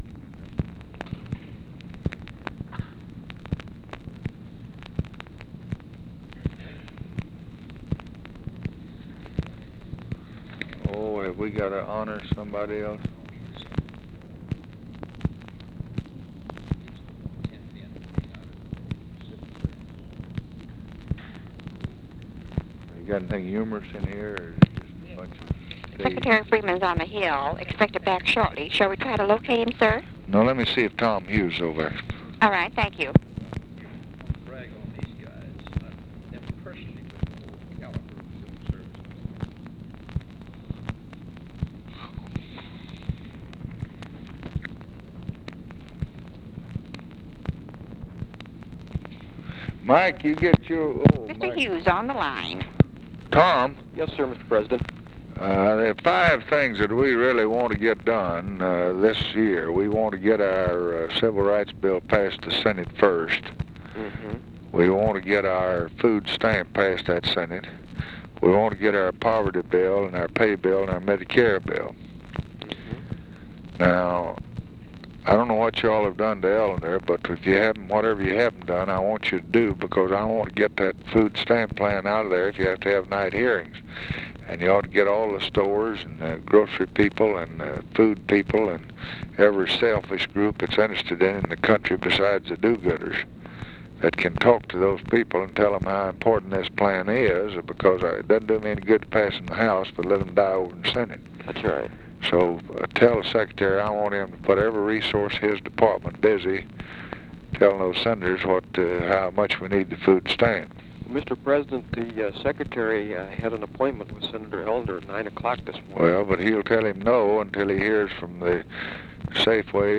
Conversation with OFFICE CONVERSATION, April 14, 1964
Secret White House Tapes